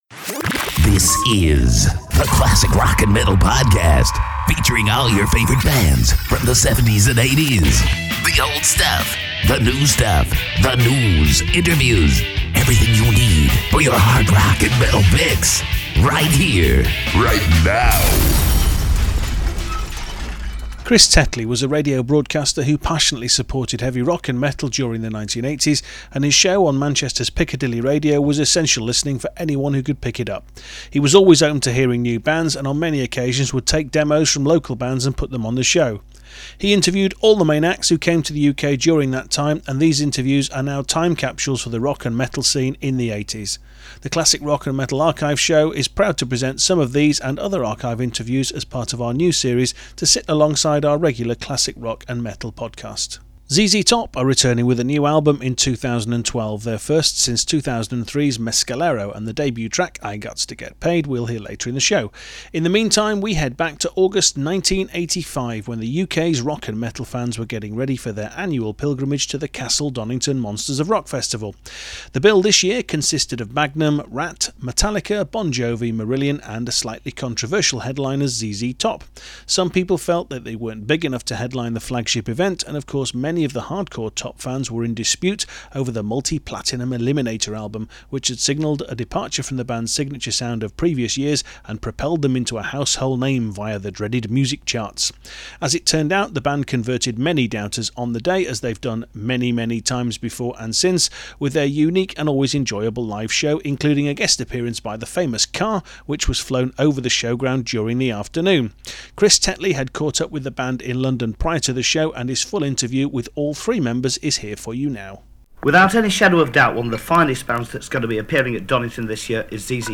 Our Classic Rock & Metal Archive series where we feature interviews from the vaults.